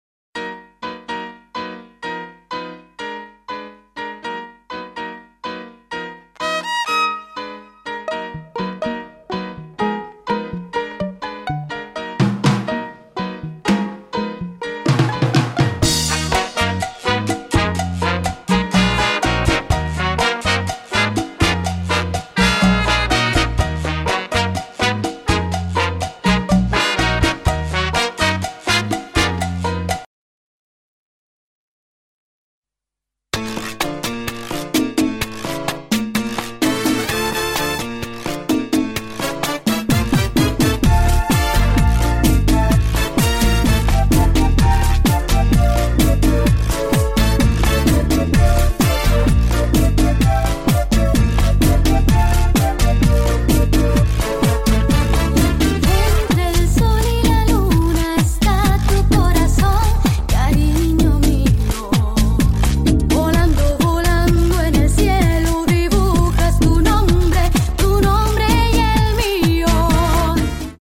音乐是剪后合成的，一并附上：